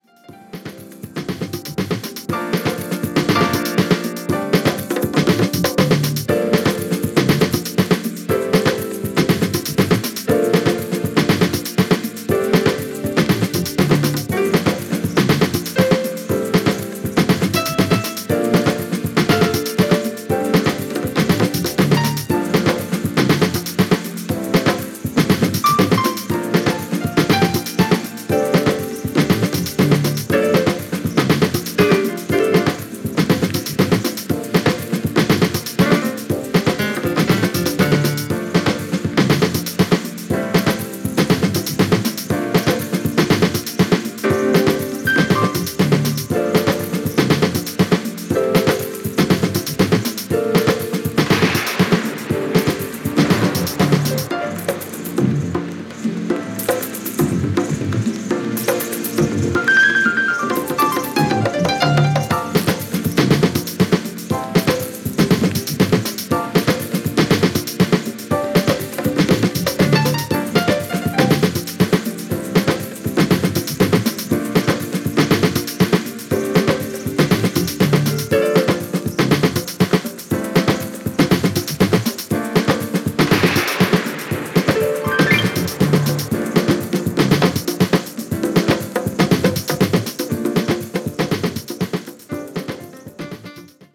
Jazzy House！！！